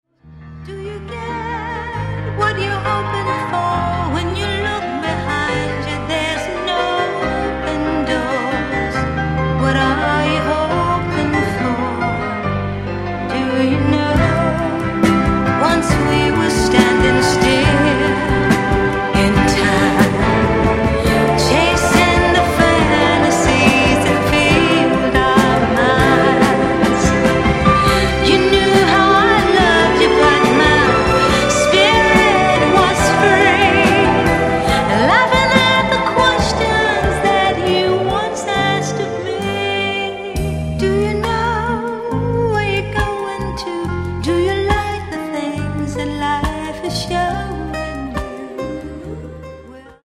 VOLUME :: 69 :: - LOVE BALLADS -